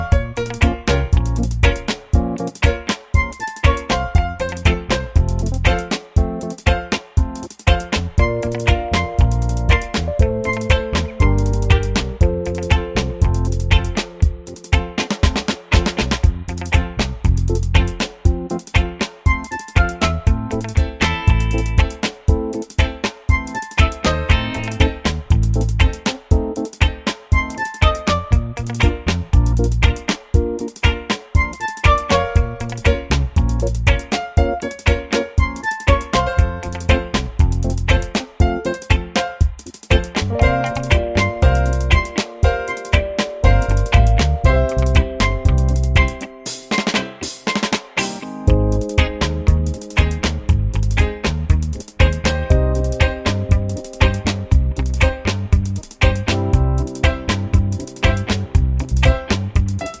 no Backing Vocals Reggae 3:59 Buy £1.50